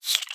Minecraft Version Minecraft Version 1.21.4 Latest Release | Latest Snapshot 1.21.4 / assets / minecraft / sounds / mob / silverfish / say3.ogg Compare With Compare With Latest Release | Latest Snapshot